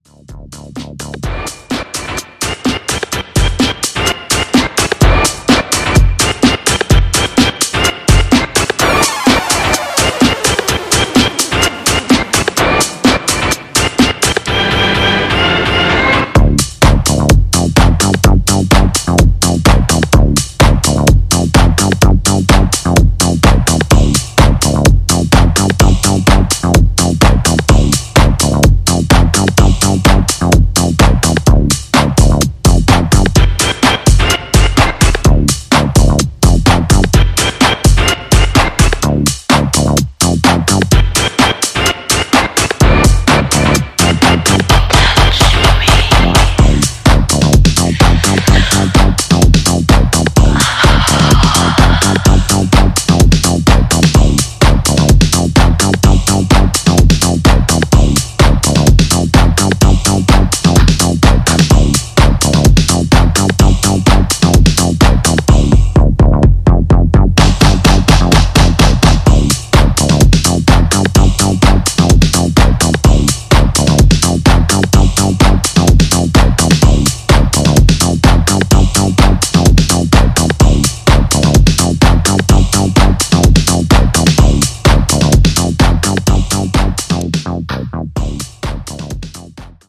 本作では、クラシカルなエレクトロや初期UKハウス、アシッド/プロト・ハウス等をオマージュした楽曲を展開。